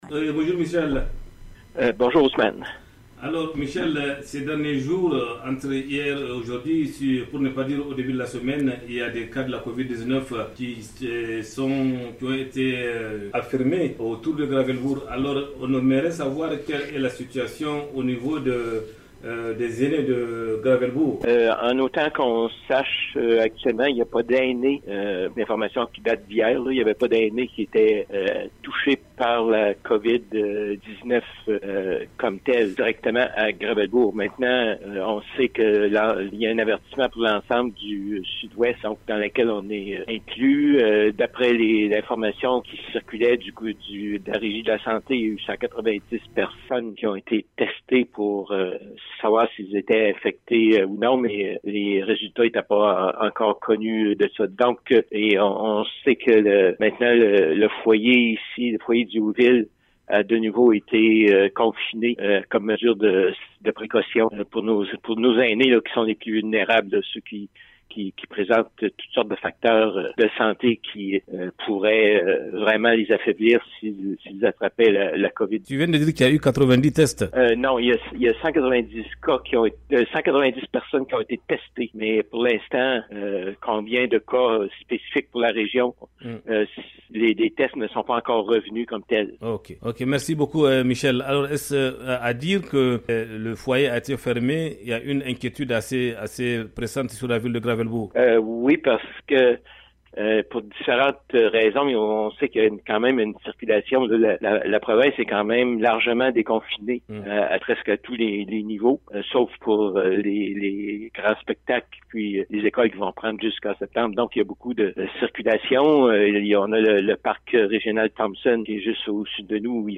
Reçu au téléphone